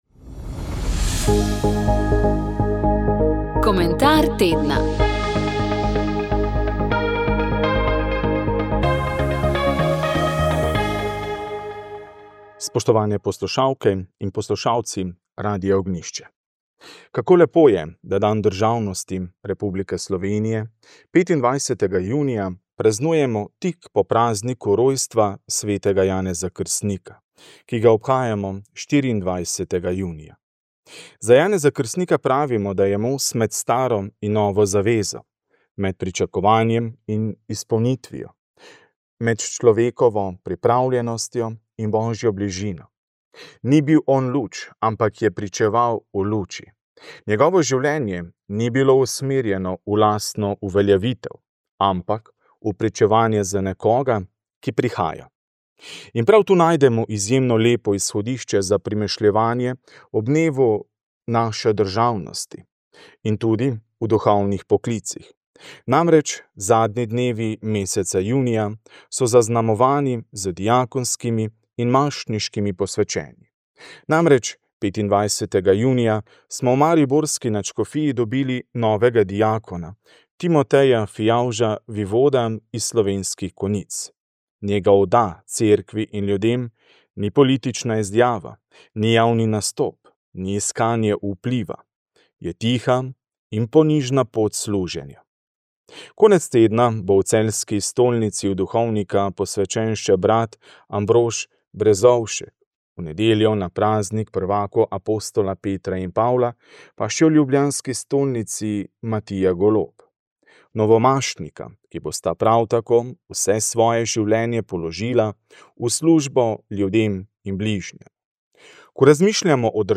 pričevanje